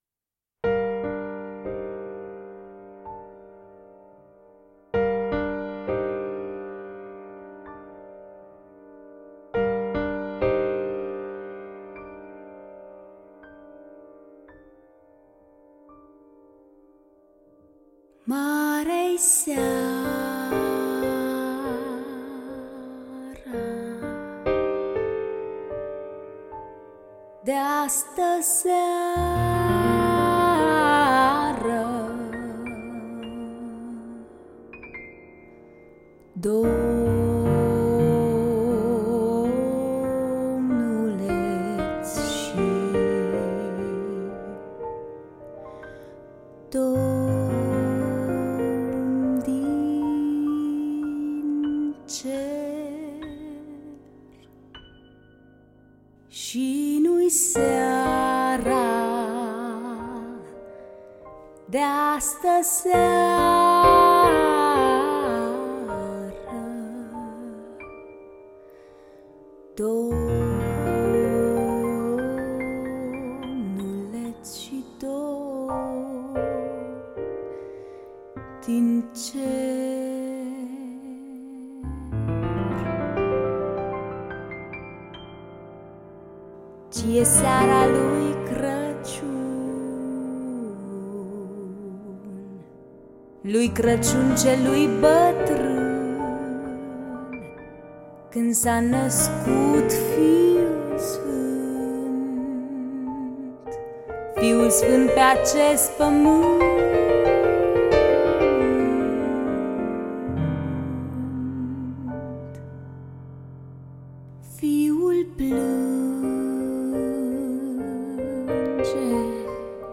Äänitetty Cluj-Napocassa vuonna 2011.